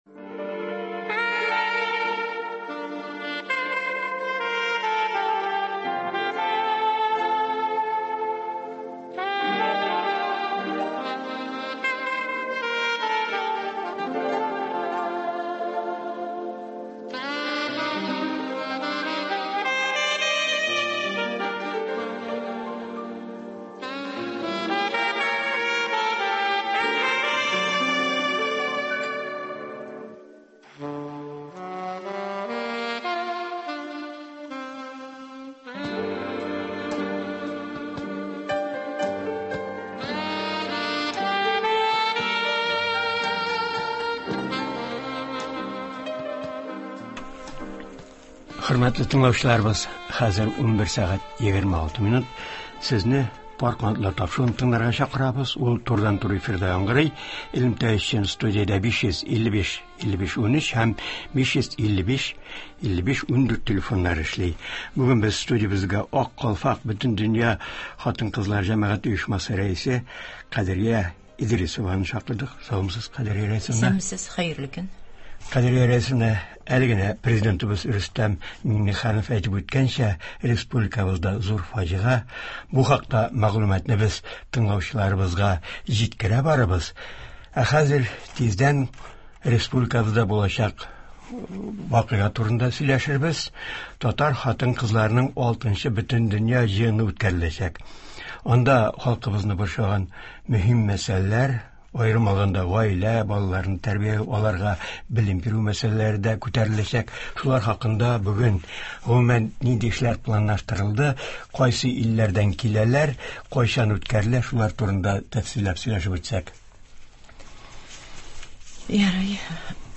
турыдан-туры эфирда сөйли, тыңлаучылар сорауларына җавап бирә.